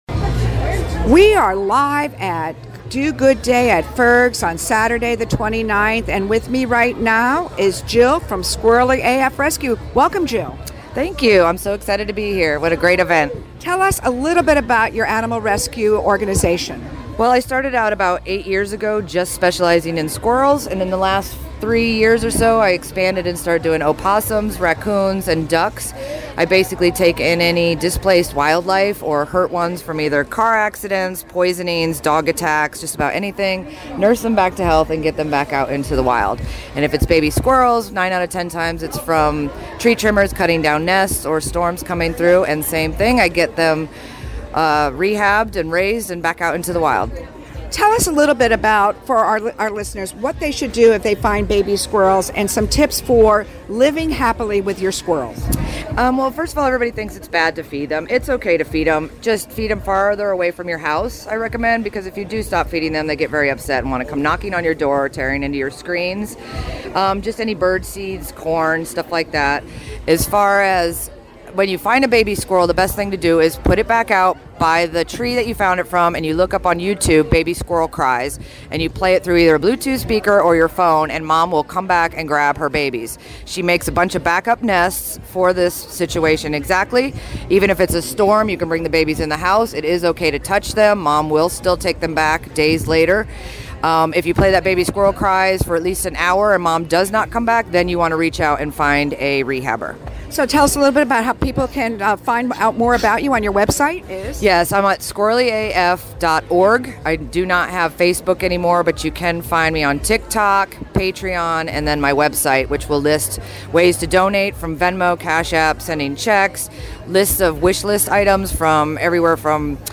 RadioStPete participated in Do Good Day at Fergs Sports Bar in downtown St. Pete March 29, interviewing some of the 35 non-profits sharing their mission and how you can volunteer and donate.